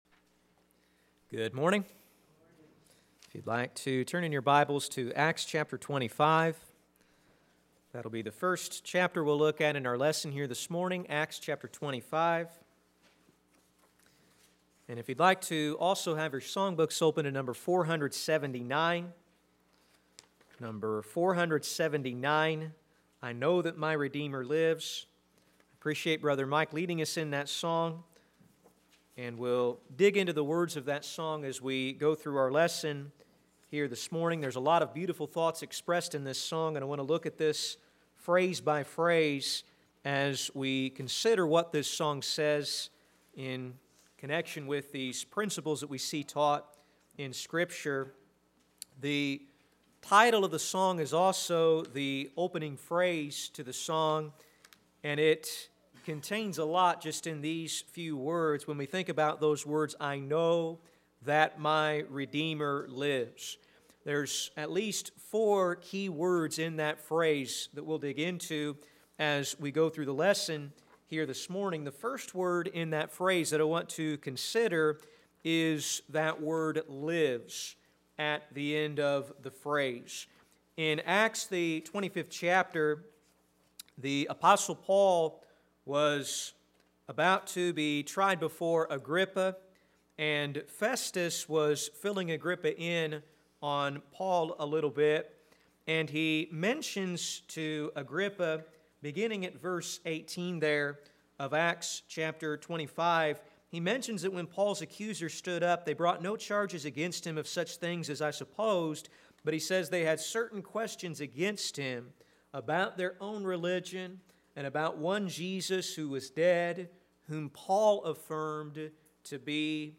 Sermons - Olney Church of Christ
Service: Sunday AM